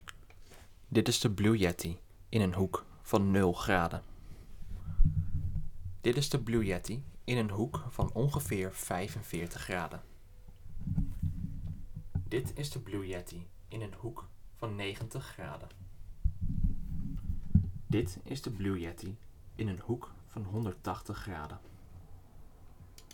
Blue Yeti - Off-axis hoekentest
Blue-Yeti-graden.m4a